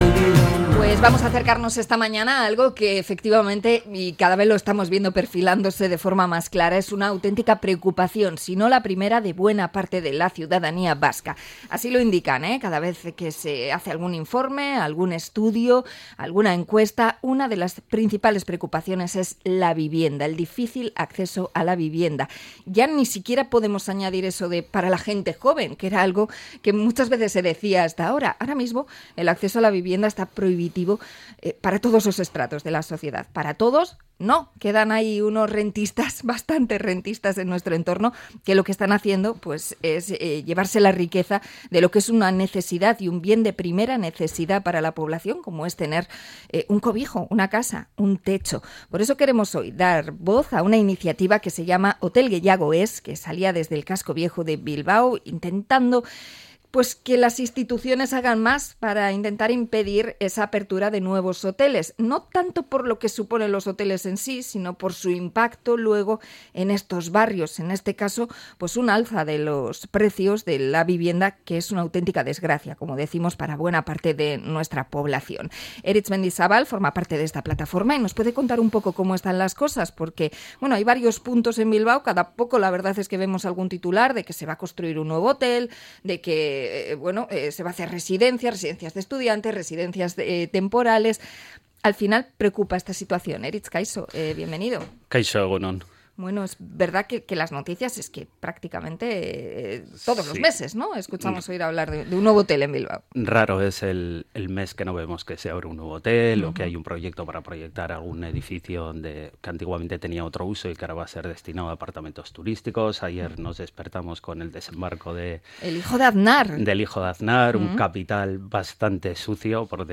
Entrevista a la plataforma Hotel gehiago Ez!